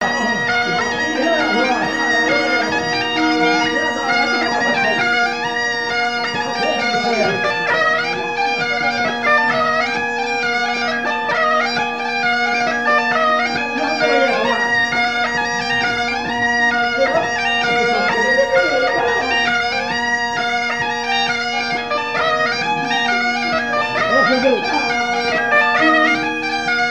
Courante par Sonneurs de veuze
danse : branle : courante, maraîchine
Airs joués à la veuze et au violon et deux grands'danses à Payré, en Bois-de-Céné
Pièce musicale inédite